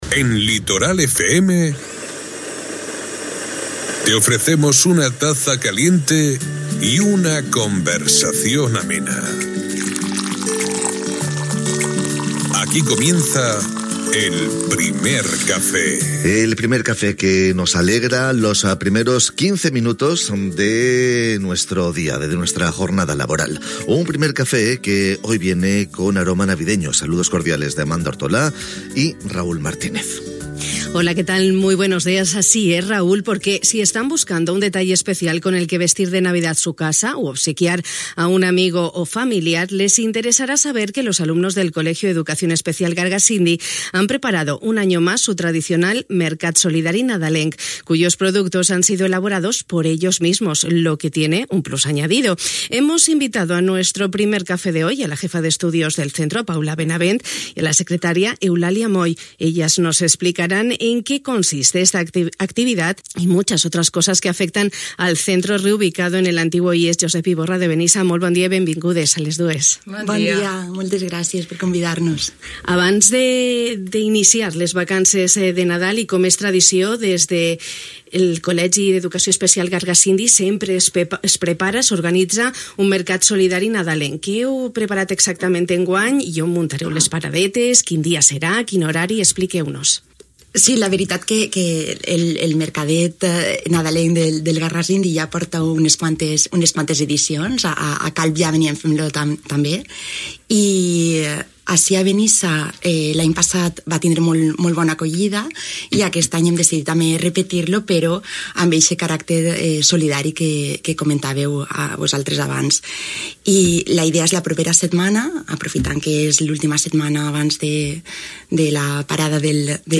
Hem compartit el nostre temps de ràdio amb dos representants de l'equip directiu d'aquest centre educatiu reubicat des de fa uns anys a l'antic IES de Benissa